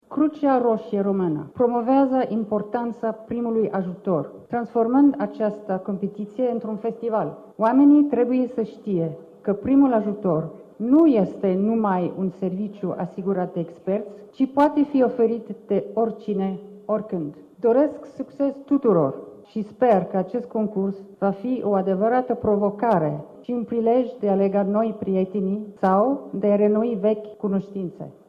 Președintele Crucii Roșii Române, Alteța sa Regală, Principesa Margareta, a precizat că acordarea primului ajutor este o dovadă de solidaritate și de iubire: